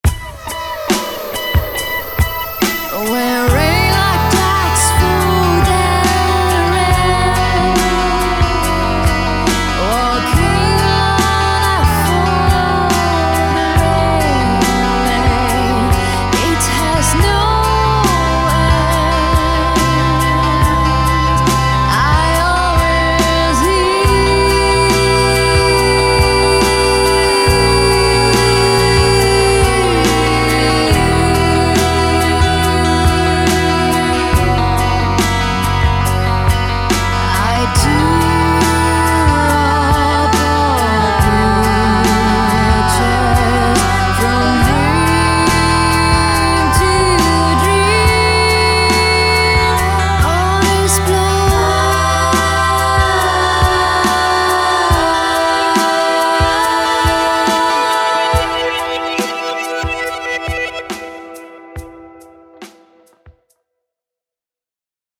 Dans un esprit plus Radioheadesque, avec une guitare discrète